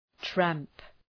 tramp Προφορά
{træmp}